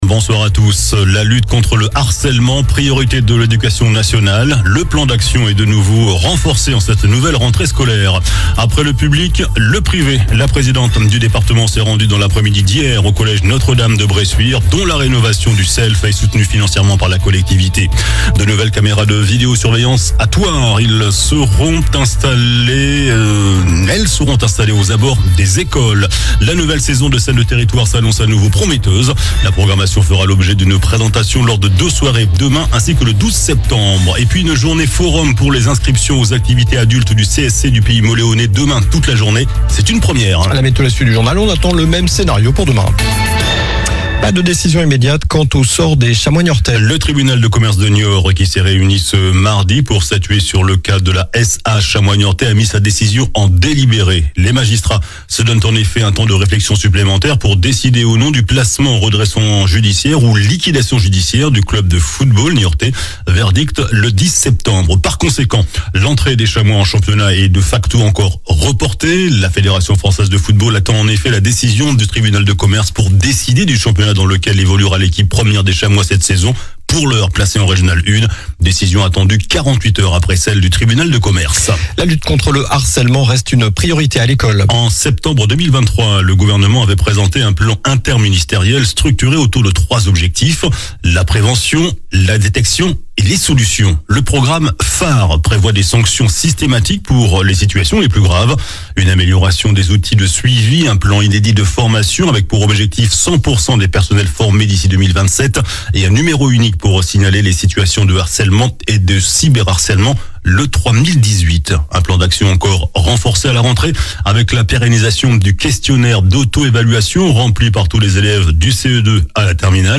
JOURNAL DU MARDI 03 SEPTEMBRE ( SOIR )